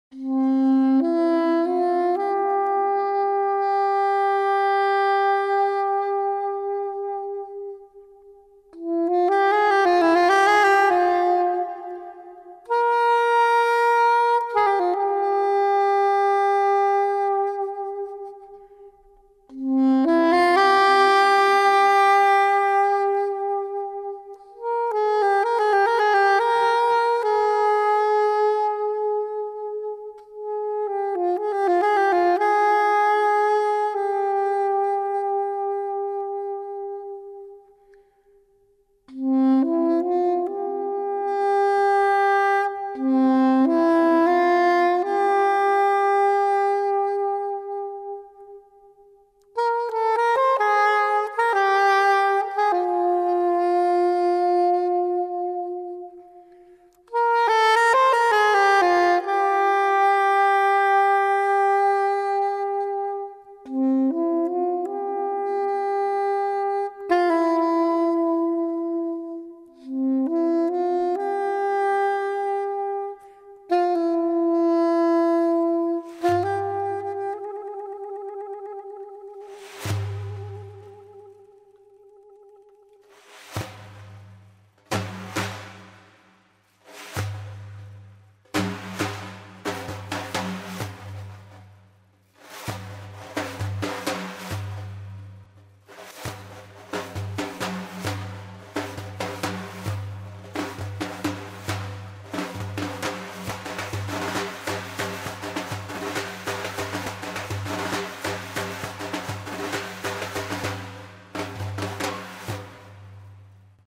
Saxophone Solo, Instrumental